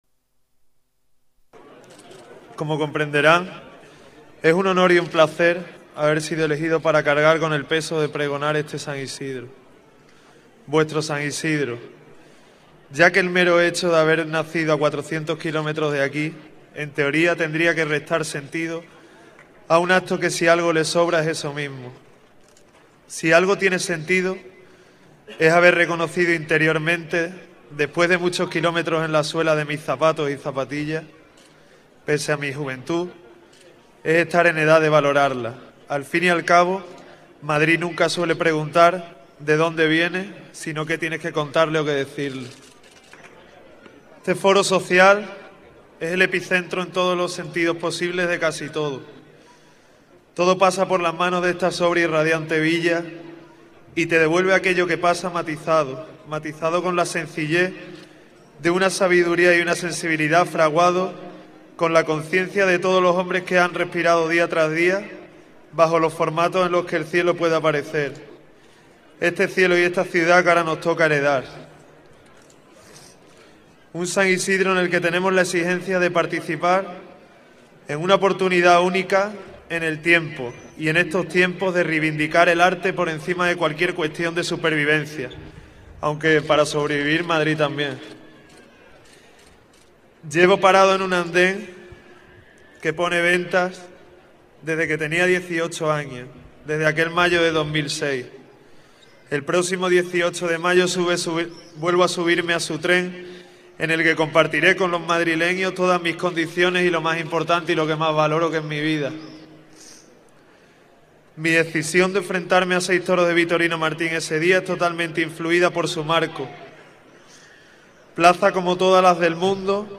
Pregón de las fiestas de San Isidro - Ayuntamiento de Madrid
Nueva ventana:Alejandro Talavante, pregón íntegro